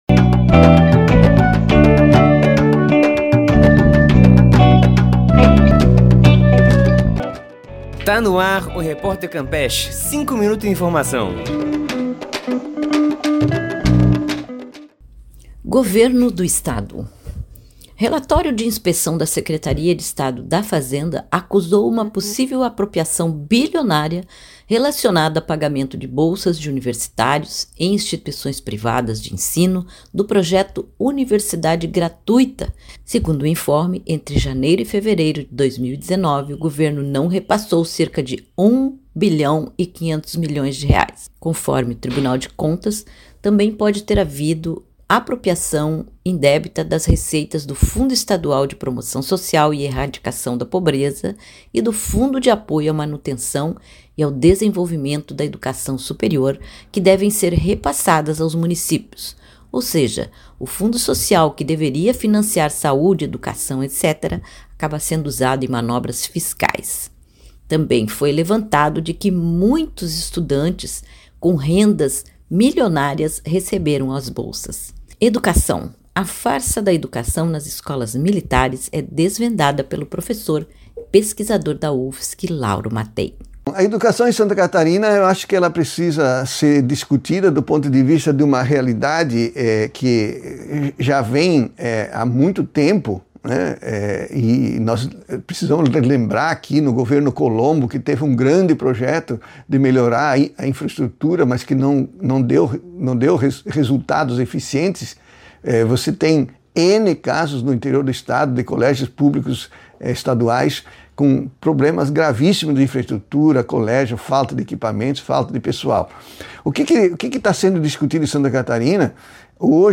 A notícia em cinco minutos – Novidades do bairro, da cidade e do mundo.